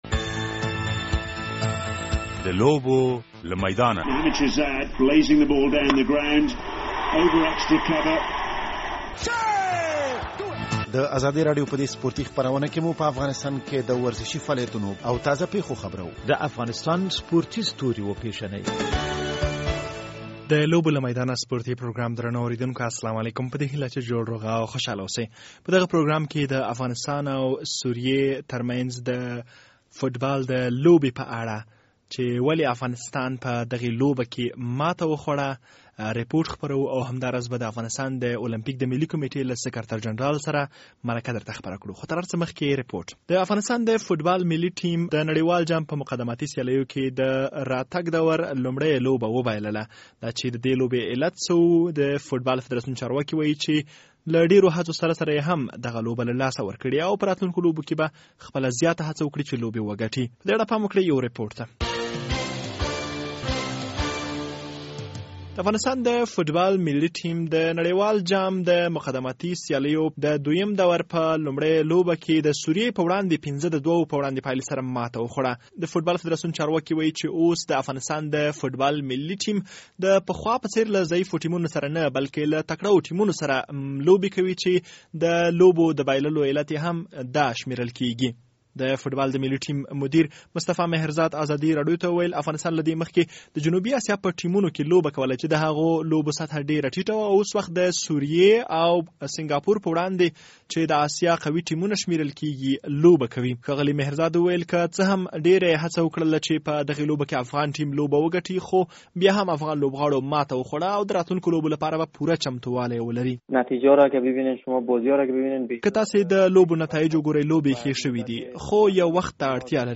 په دغه پروګرام کې د المپيک د ملي کمیټې د یوې عمومي غونډې په اړه درته مرکه خپریږي او همدا راز به د فوټبال د ملي ټیم د وروستیو ماتو په اړه دلایل واورئ.